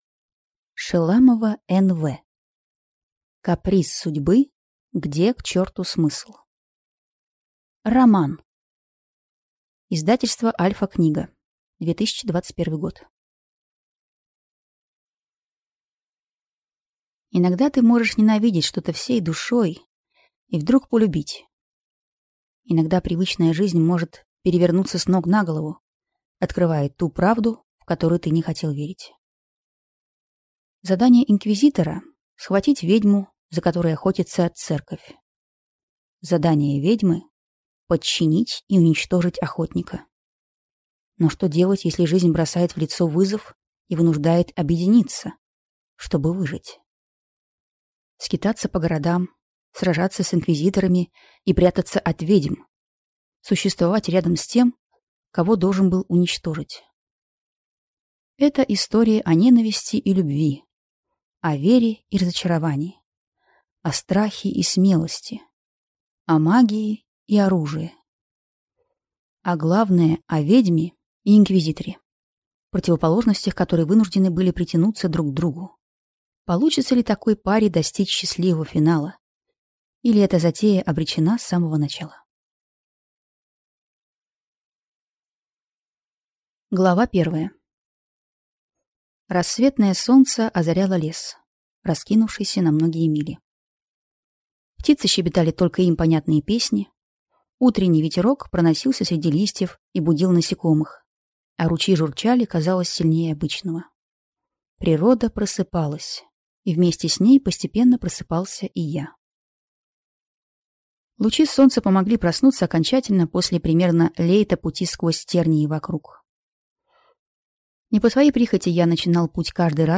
Аудиокнига Каприз судьбы. Где, к чёрту, смысл?